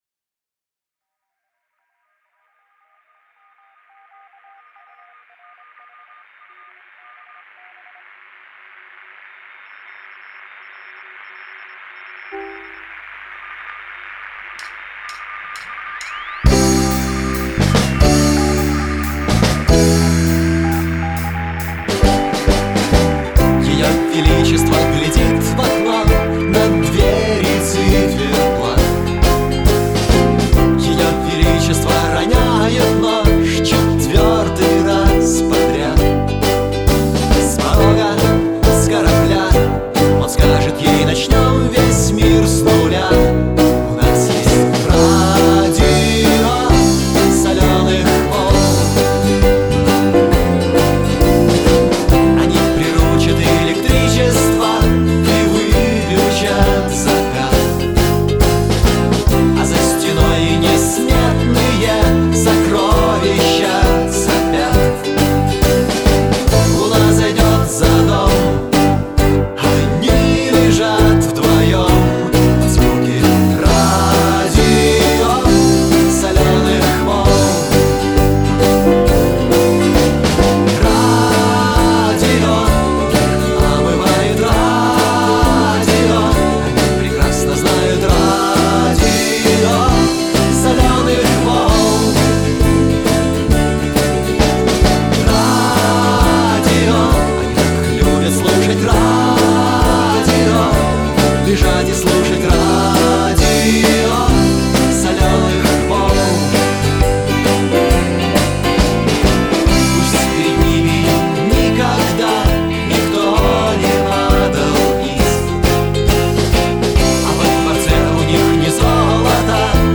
Да, помеховая обстановка не фонтан.
Местами слышна партия баса
01_Radio_SW.mp3